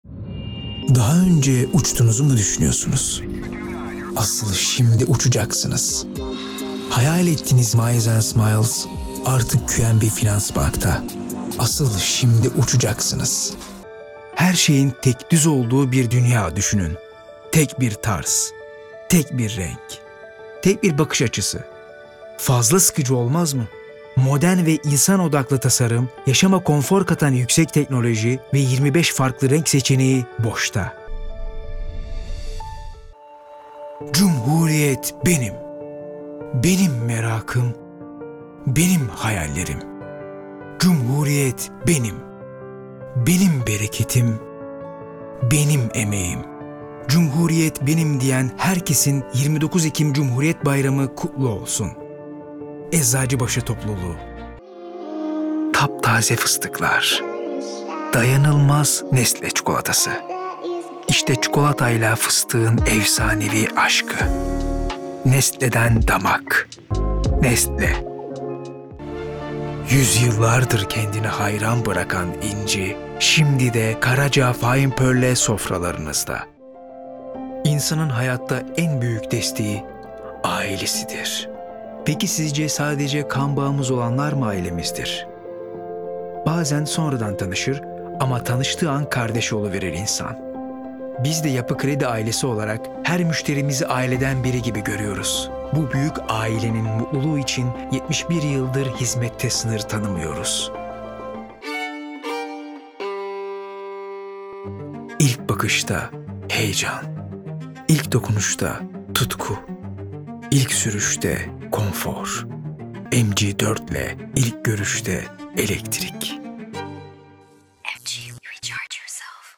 Female 20s , 30s British English (Native) Confident , Engaging , Friendly , Reassuring , Versatile , Approachable , Bright , Bubbly , Character , Conversational , Corporate , Natural , Upbeat , Witty , Young